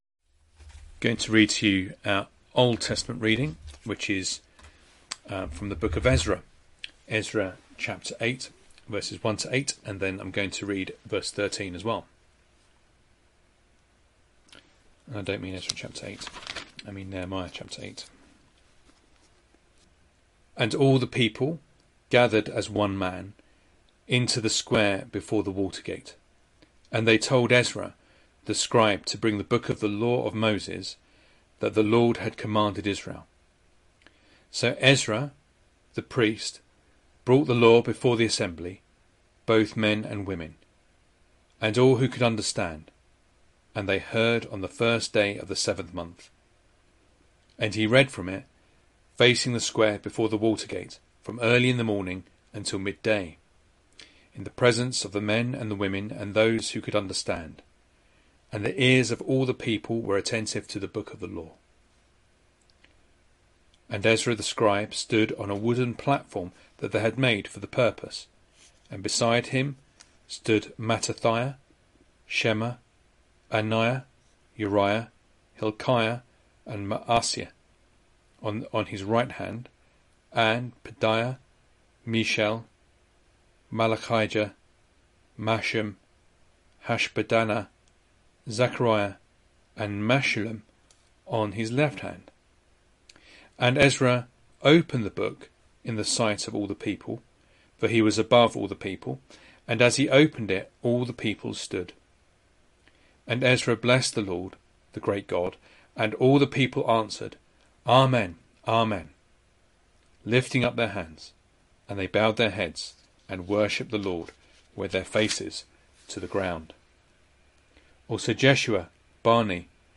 Sunday Morning Reading and Sermon Audio